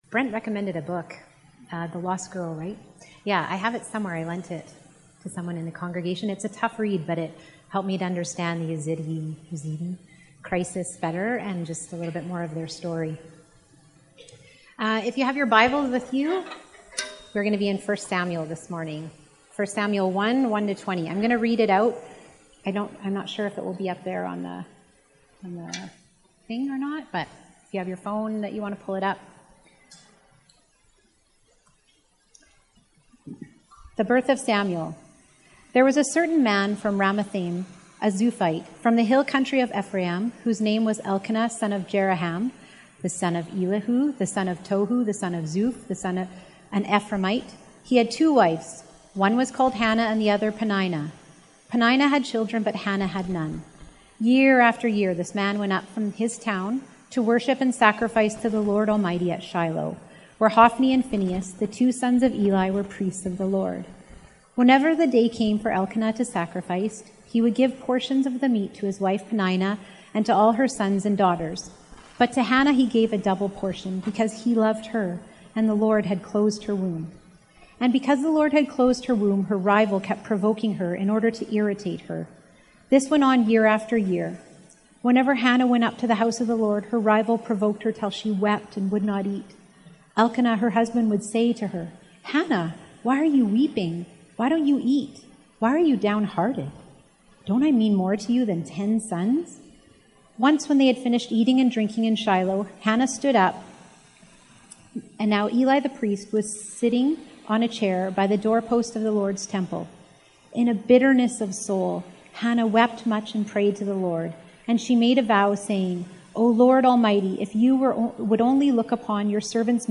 Sermons | Crossroads Community Church of the Christian and Missionary Alliance in Canada